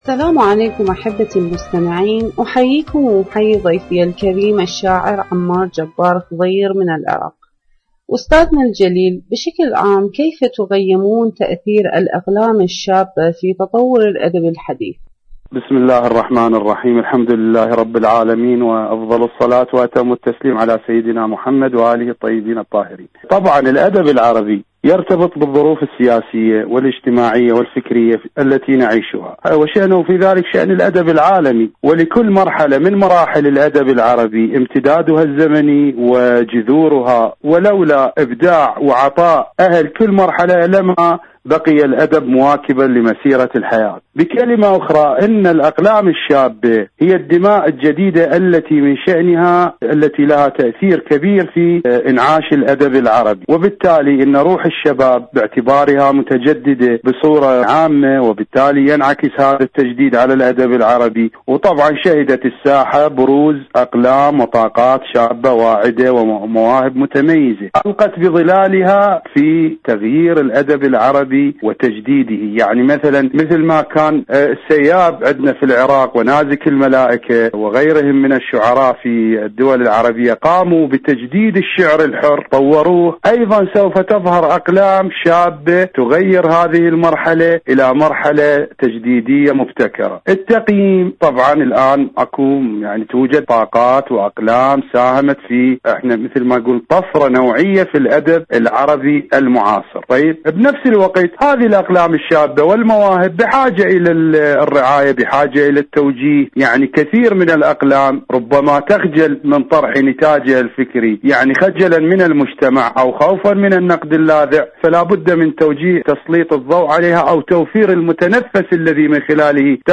إذاعة طهران-دنيا الشباب: مقابلة إذاعية